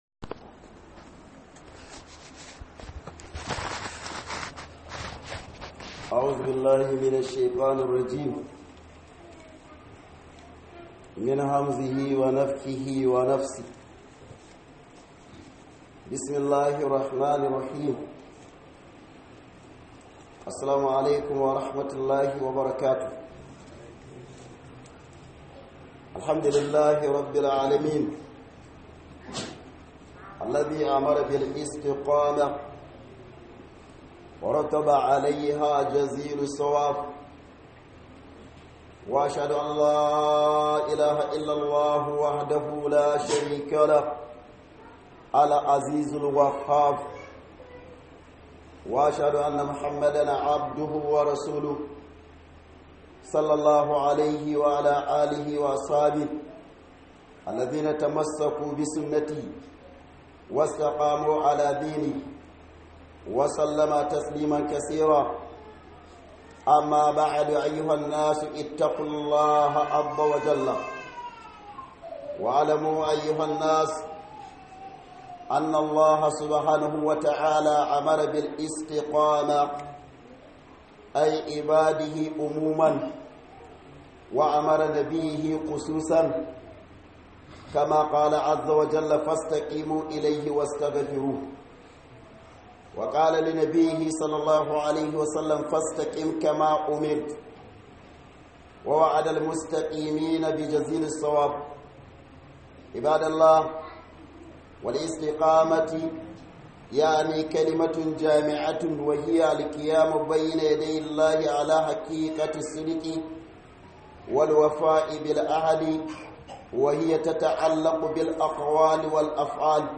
Khudubar Sallar Juma'a